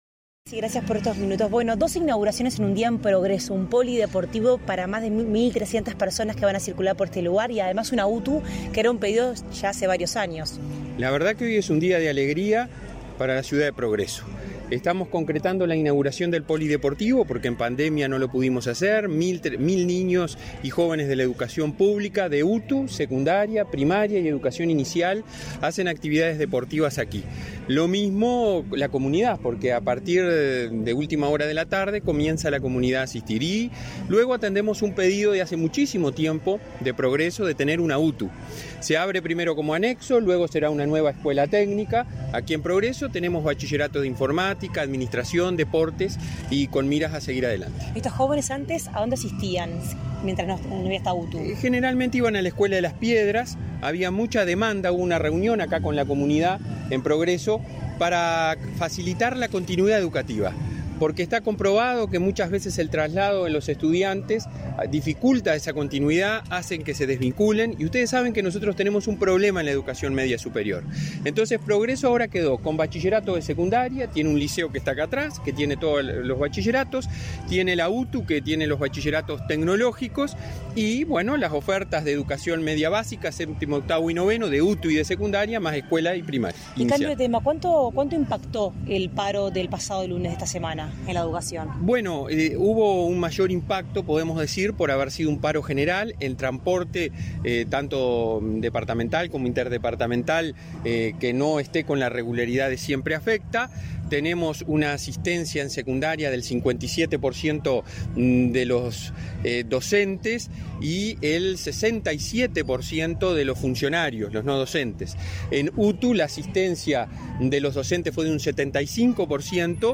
Declaraciones a la prensa del presidente de la ANEP, Robert Silva
Tras el evento, el presidente de ANEP, Robert Silva, realizó declaraciones a la prensa.